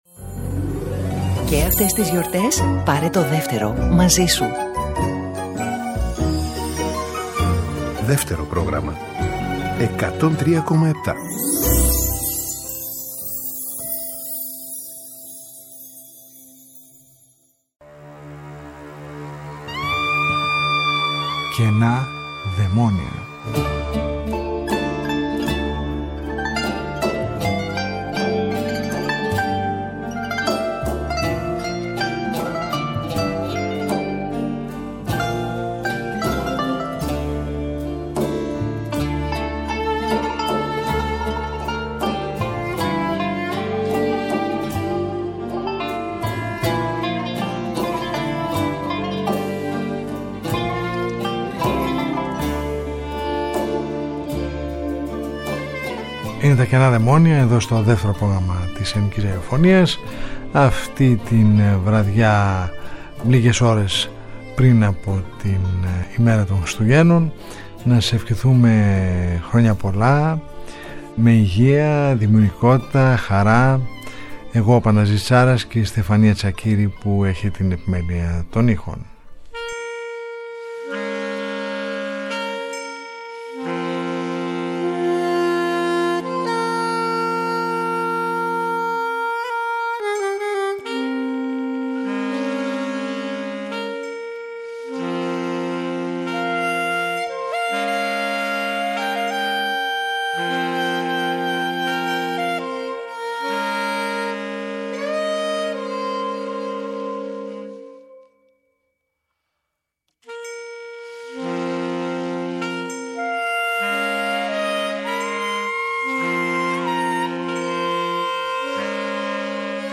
διανθισμένο από πολύ γνωστές Χριστουγεννιάτικες μελωδίες και τραγούδια της Ελληνικής παραδοσιακής μουσικής.
Καινά Δαιμόνια : Μια ραδιοφωνική συνάντηση κάθε Σάββατο στις 22:00 που μας οδηγεί μέσα από τους ήχους της ελληνικής δισκογραφίας του χθες και του σήμερα σε ένα αέναο μουσικό ταξίδι.